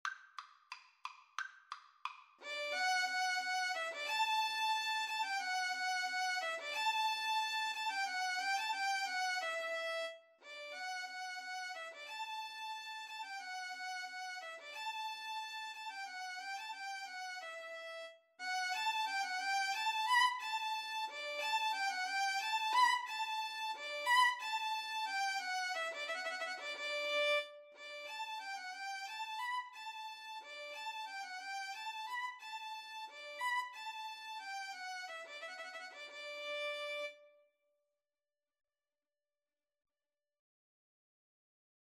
Play (or use space bar on your keyboard) Pause Music Playalong - Player 1 Accompaniment transpose reset tempo print settings full screen
D major (Sounding Pitch) (View more D major Music for Violin-Cello Duet )
=180 Vivace (View more music marked Vivace)
Classical (View more Classical Violin-Cello Duet Music)